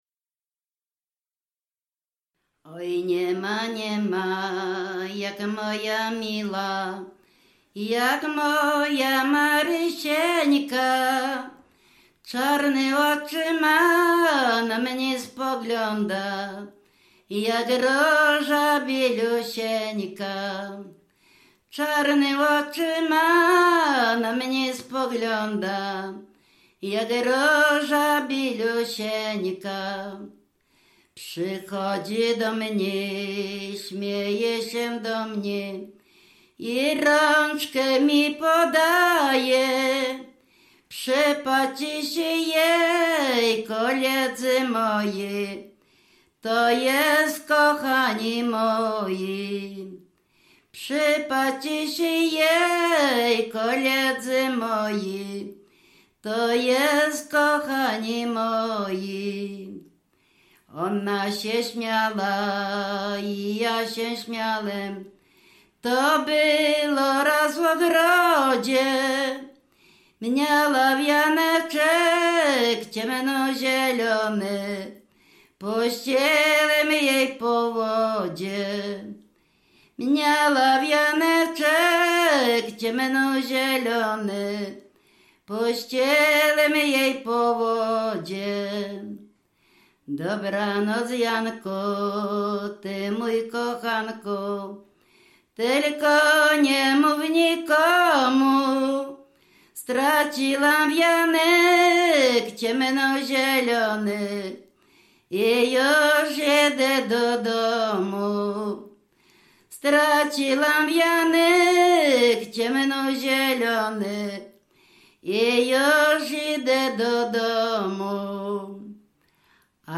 W wymowie Ł wymawiane jako przedniojęzykowo-zębowe;
e (é) w końcu wyrazu zachowało jego dawną realizację jako i(y)
Obyczajowa
liryczne miłosne